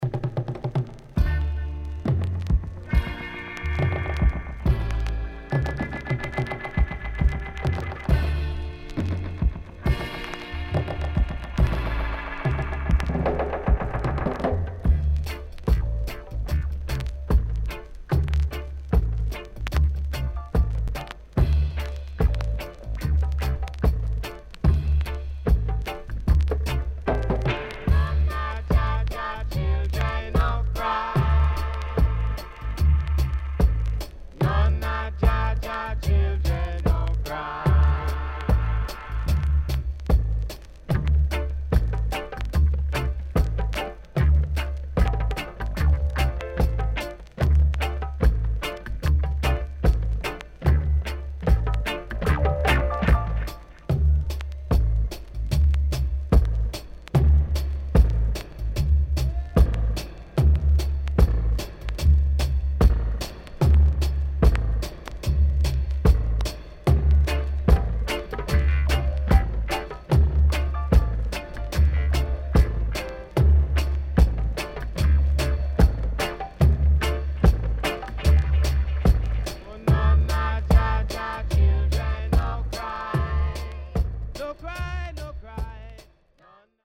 HOME > Back Order [VINTAGE 7inch]  >  KILLER & DEEP
Deep Roots Vocal & Dubwise
SIDE A:所々チリノイズがあり、少しプチノイズ入ります。